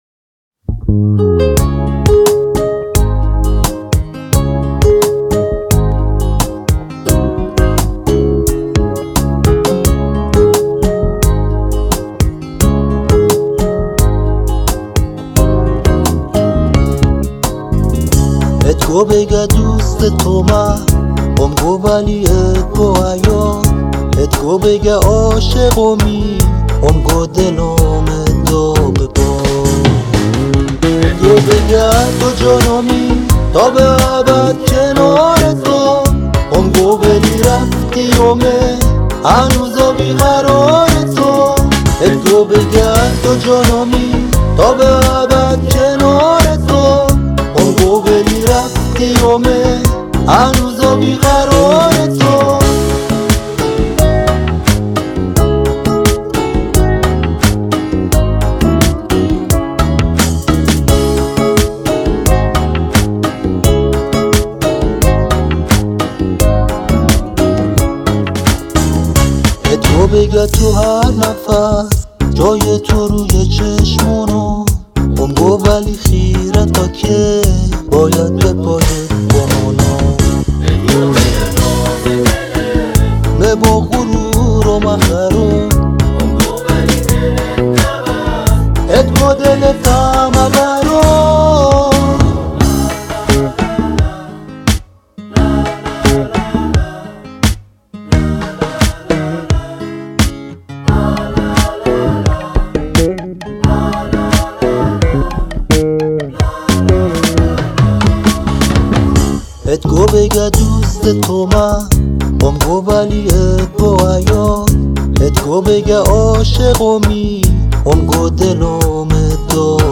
🎛 ضبط: استودیو صدابرداری تاک